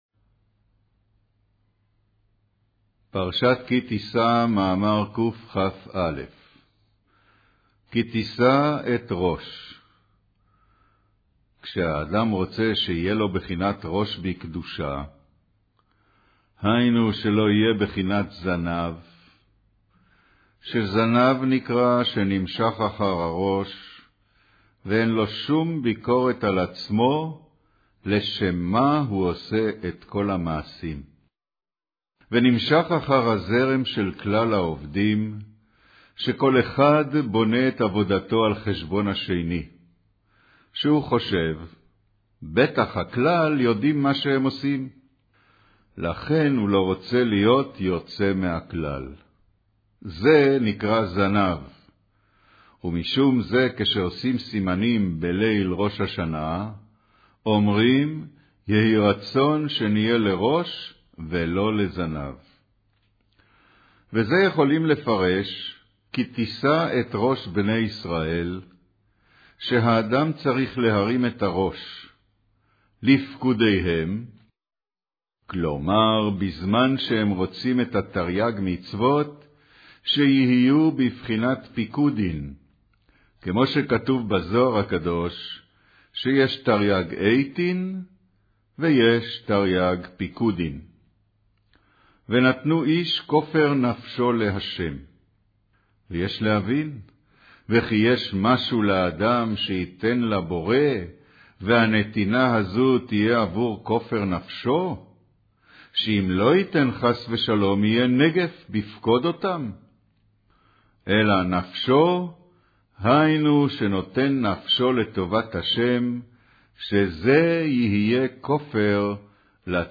אודיו - קריינות פרשת כי תשא